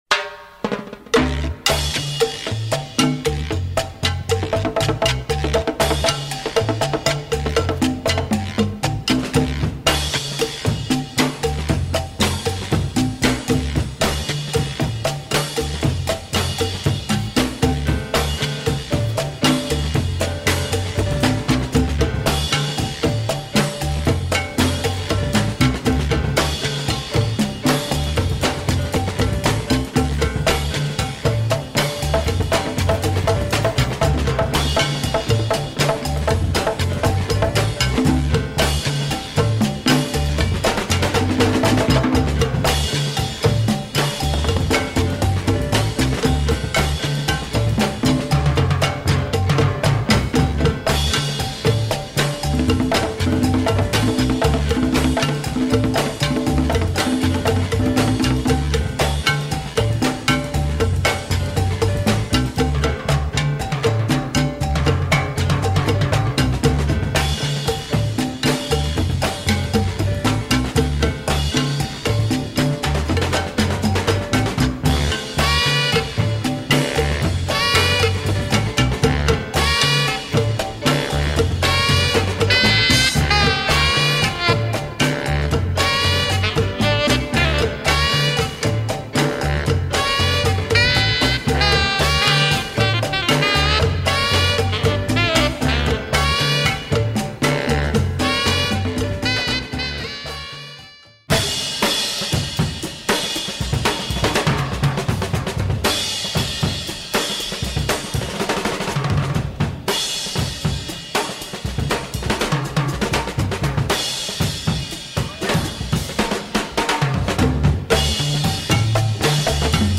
Some of his best afro cuban tunes here reunited